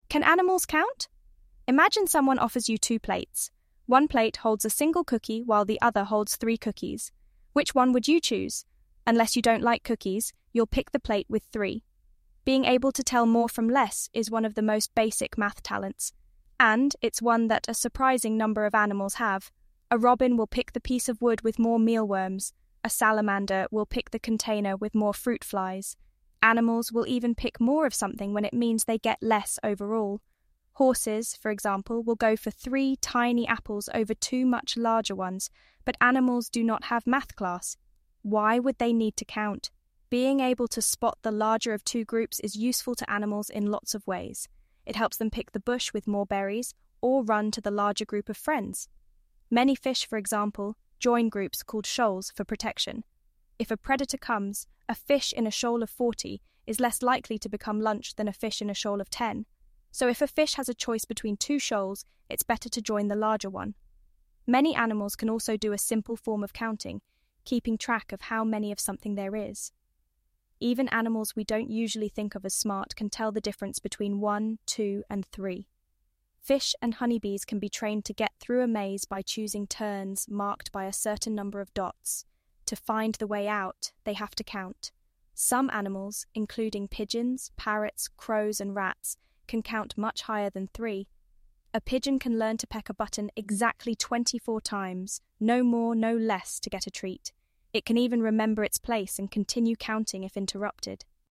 下载美音音频 美音朗读音频，可在线聆听或下载文件